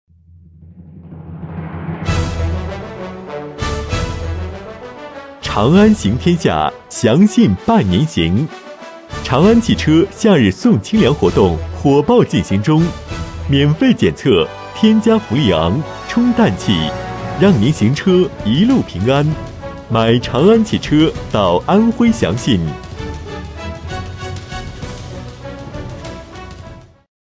【男31号促销】长安汽车
【男31号促销】长安汽车.mp3